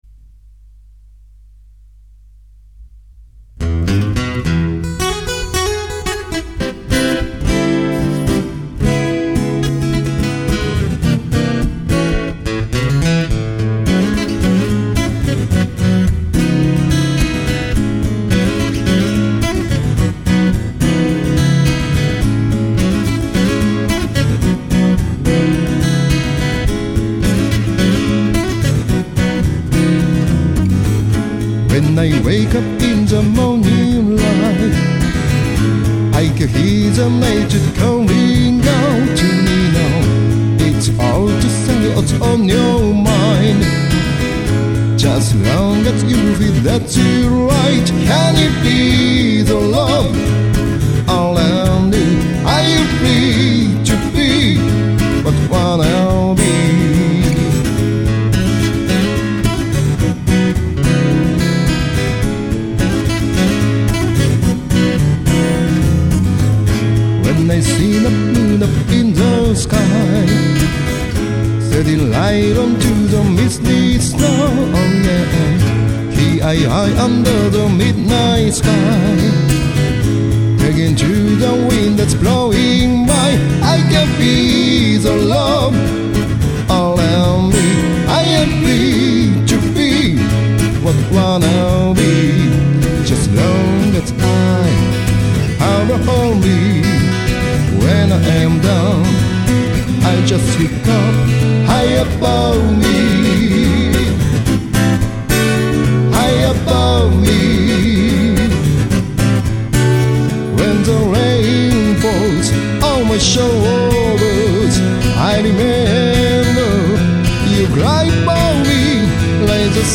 【vocal&guitar】
【guitar】